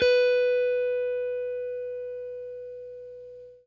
FENDERSFT AN.wav